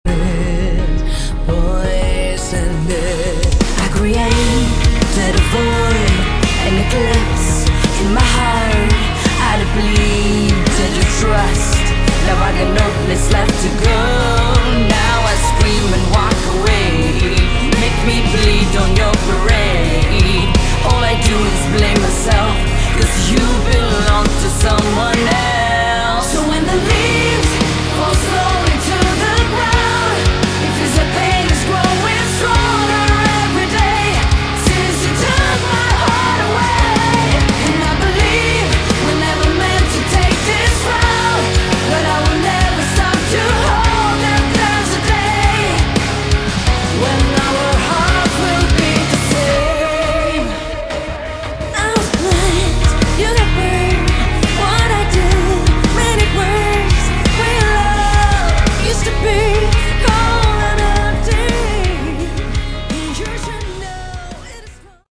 Category: Melodic Metal
vocals
guitars
bass
drums
keyboards, piano